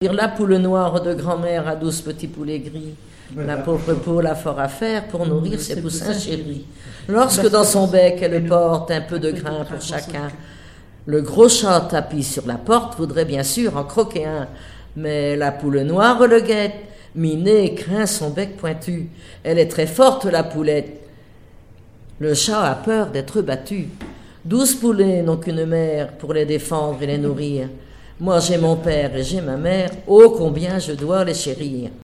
répertoire de chansons traditionnelles
Pièce musicale inédite